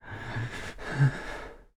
Player_UI [22].wav